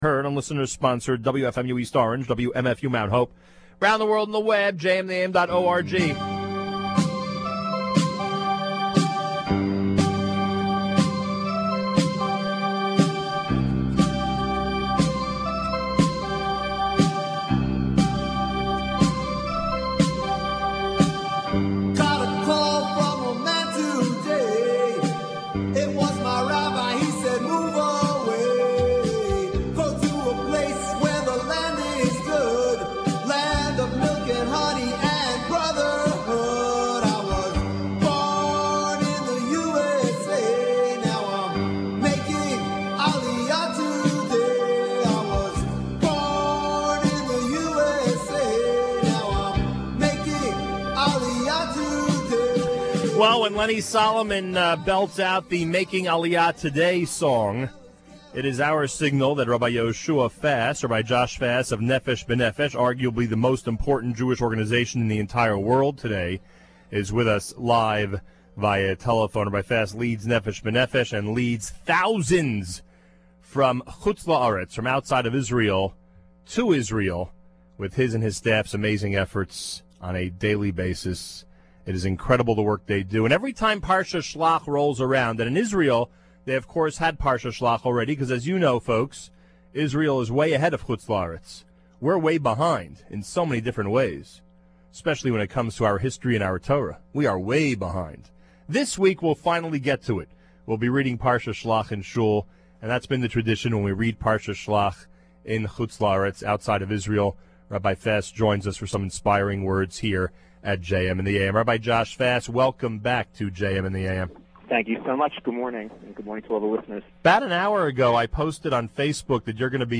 live via telephone